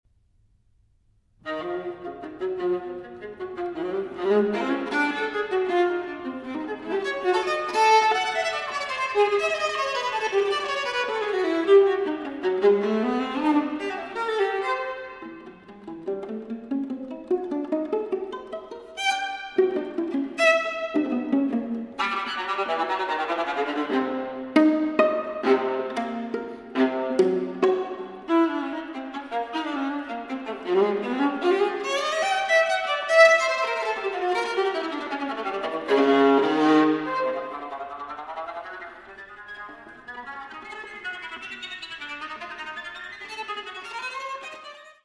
for Viola